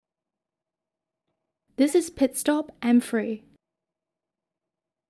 • 女粤04 香港口音英语 广告 大气浑厚磁性|沉稳|娓娓道来|科技感|积极向上|时尚活力|神秘性感|亲切甜美|素人